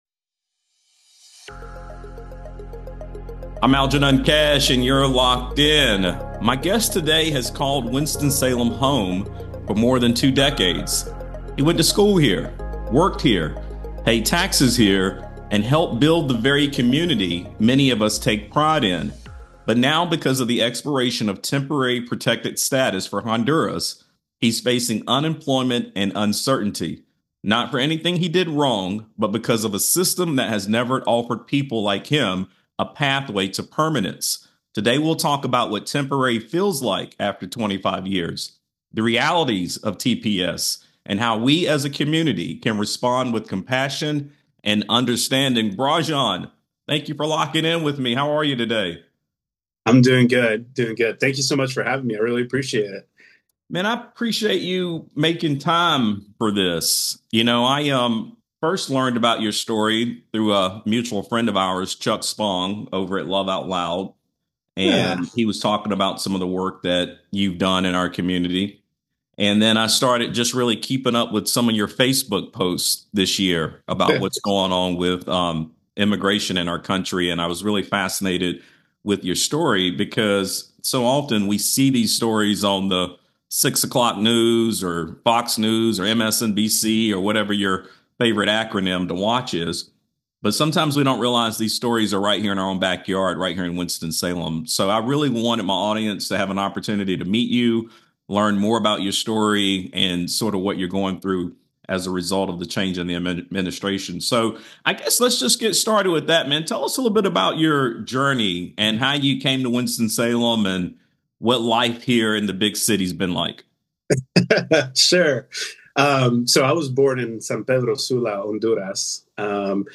Temporary After 25 Years: A Conversation on Immigration, Identity, and Hope